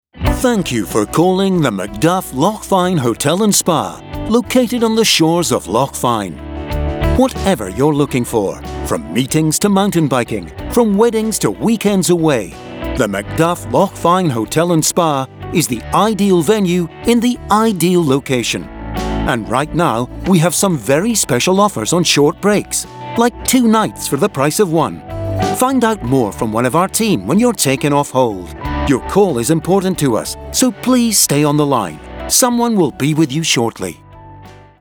Scottish Male Voice Over for On Hold & IVR
Accent: Neutral Scottish but can be more Scottish if required
Tone / Style: Versatile from Corporate to intimate and friendly